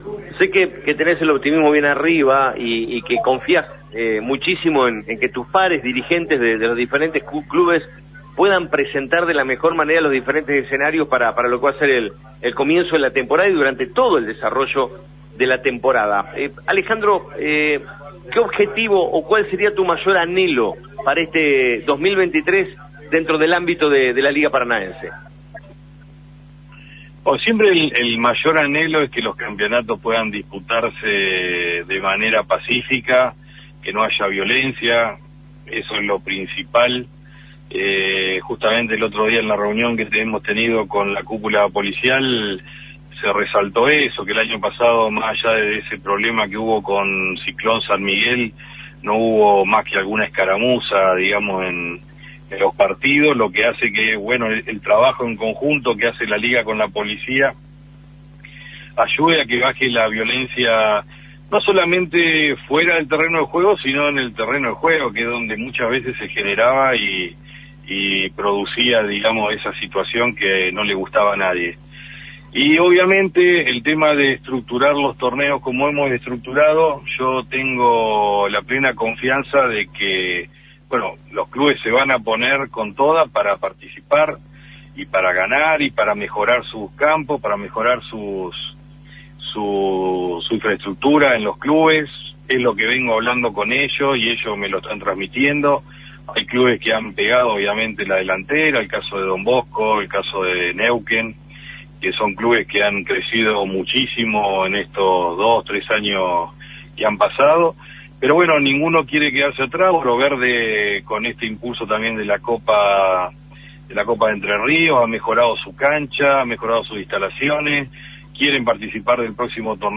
Linda charla